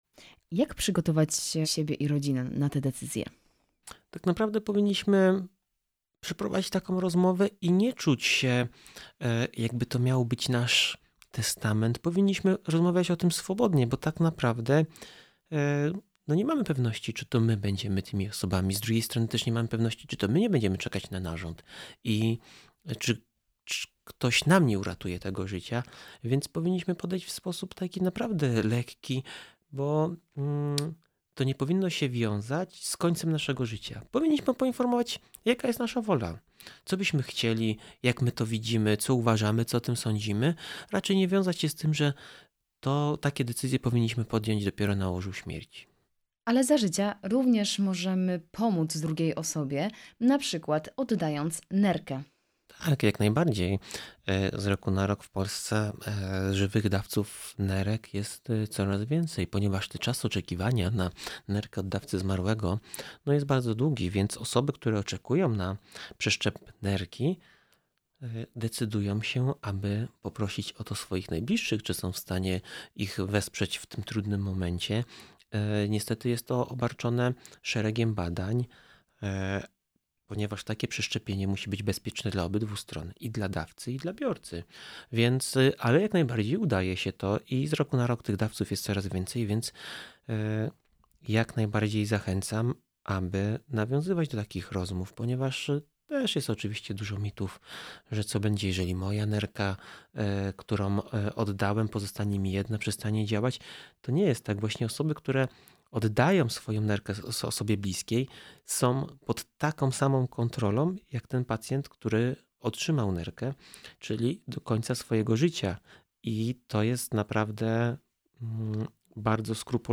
03-rozmowa-zgoda-to-dar-zycia.mp3